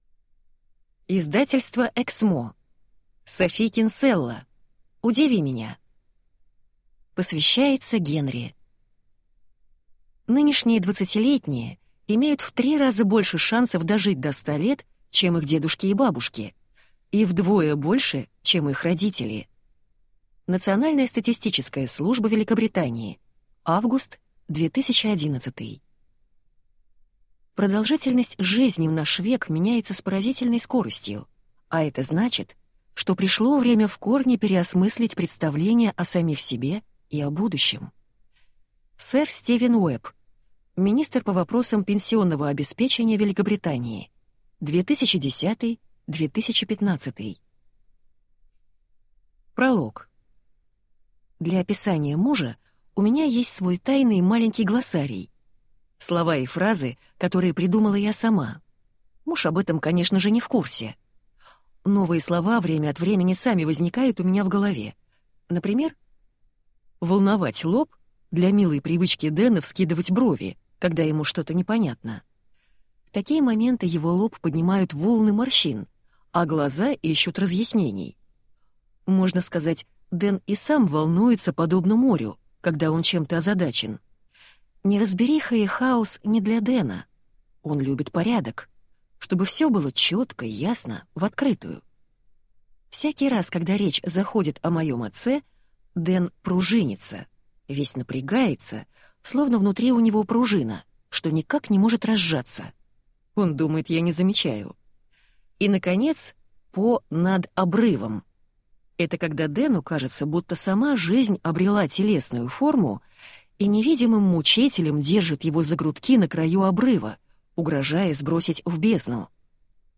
Аудиокнига Удиви меня | Библиотека аудиокниг